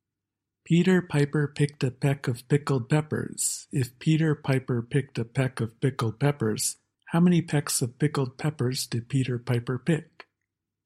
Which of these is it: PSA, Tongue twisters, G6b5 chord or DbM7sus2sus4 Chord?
Tongue twisters